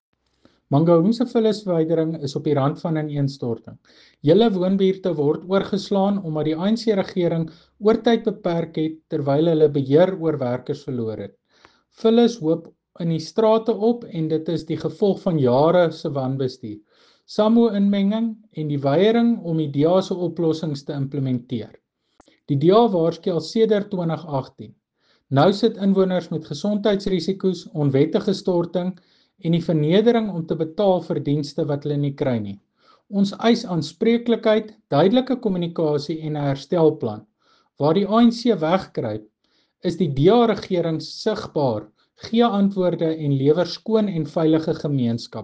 Afrikaans soundbites by Cllr Tjaart van der Walt and